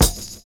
DRUM SECT 19.wav